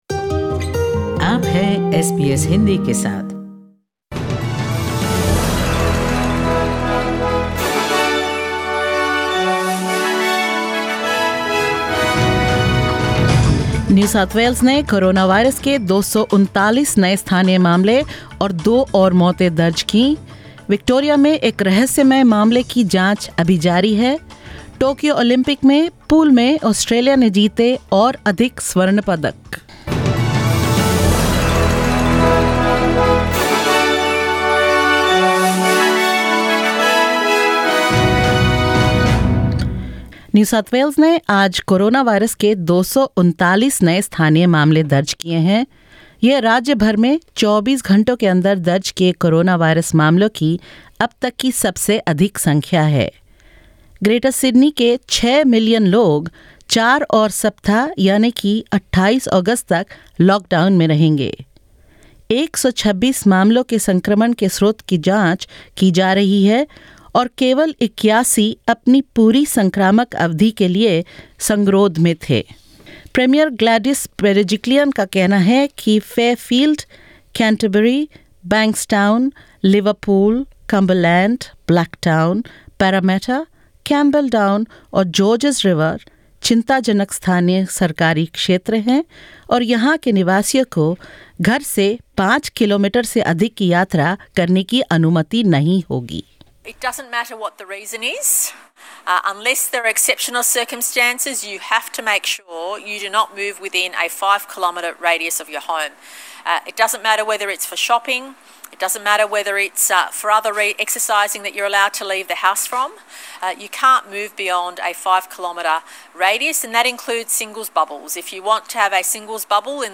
In this latest SBS Hindi News bulletin of Australia and India: New South Wales records 239 local cases of COVID-19 and two new deaths; Victoria investigates a mysterious COVID-19 case; Australia wins more gold medals in the Tokyo Olympics and the Indian men's hockey team storms into the quarterfinals.